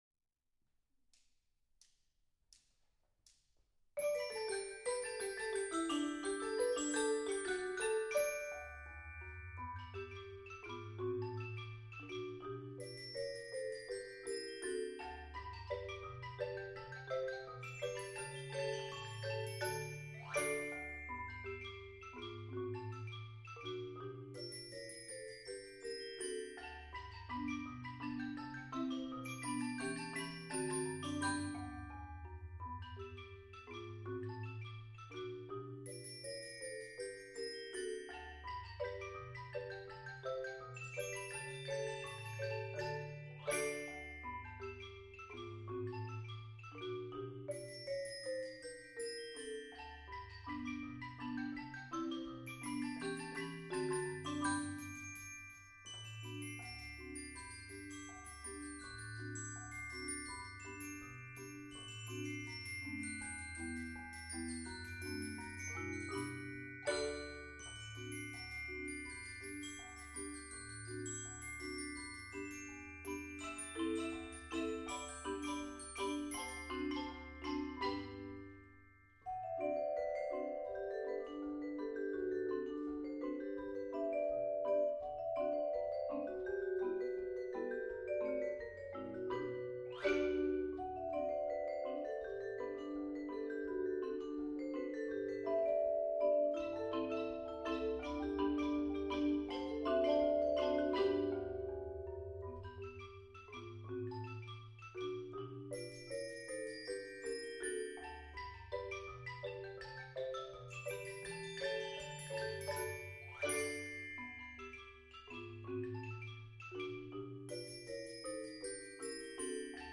Genre: Percussion Ensemble